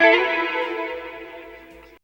137 GTR 6 -L.wav